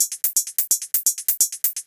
Index of /musicradar/ultimate-hihat-samples/128bpm
UHH_ElectroHatB_128-04.wav